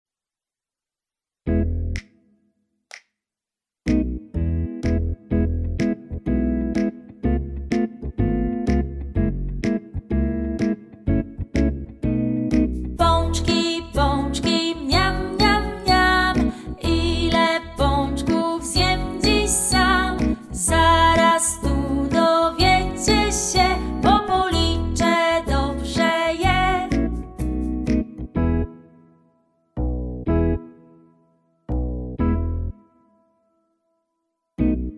utwór w wersji instrumentalnej oraz wokalnej